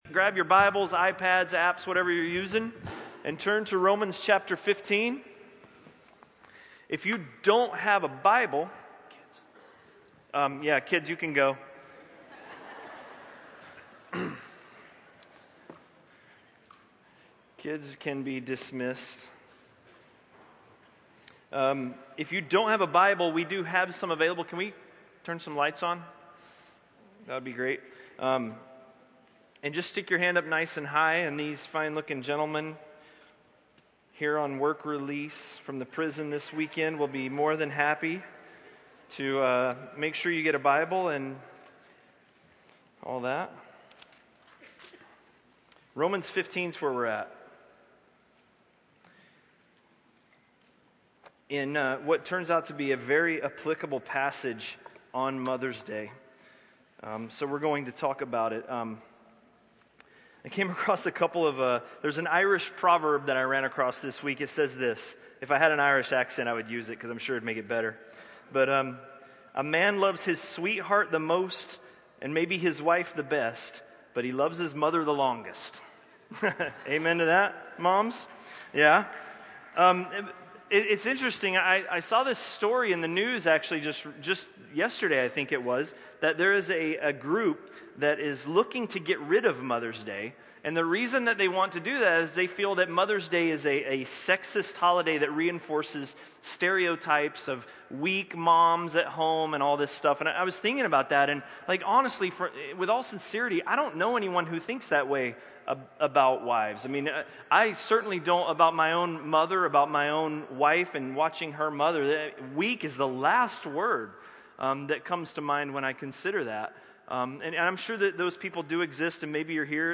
A message from the series "Romans." Romans 15:1–15:7